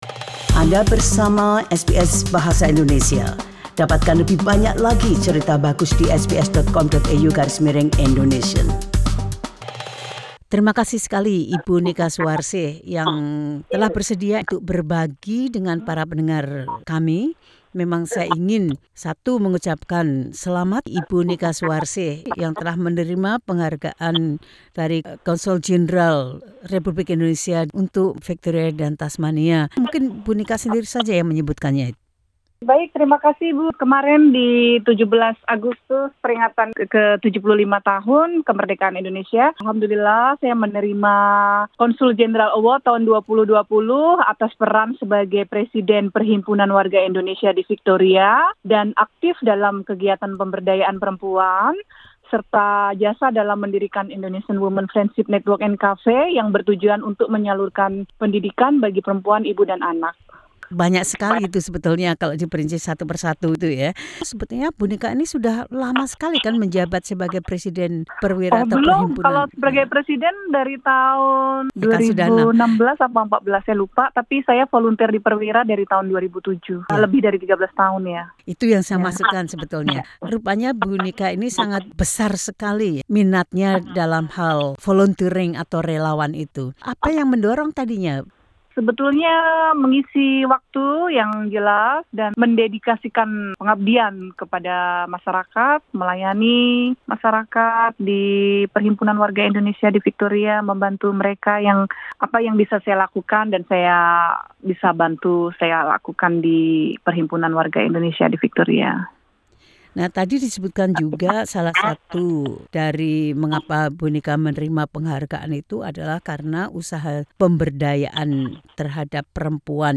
Bincang-bincang